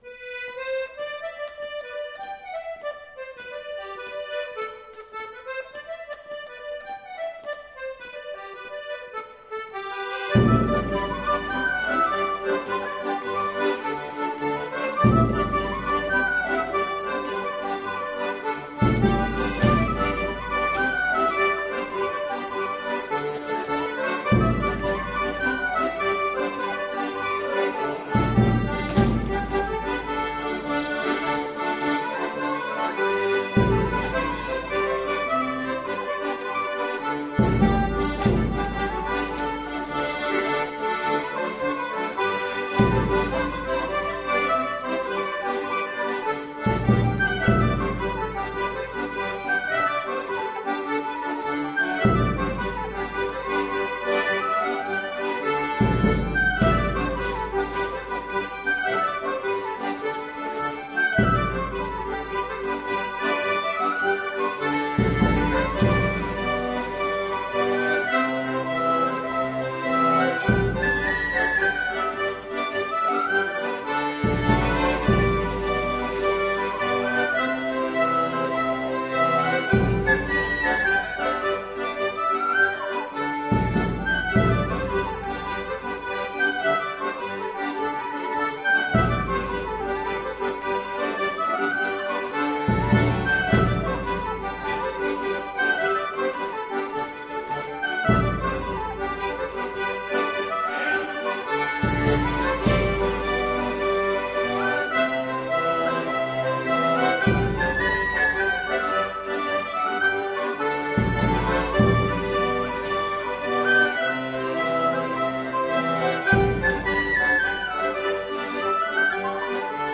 It is danced to jigs and is mainly done to a skipping step, but unusually for a dance in 6/8 time also uses the polka step.
The use of Scottish tunes was quite common in Lancashire Morris and the tune sequence for this dance not only influences the feel of the dance and the way it is performed but also serves as a reminder to the dancers as to the order of the figures.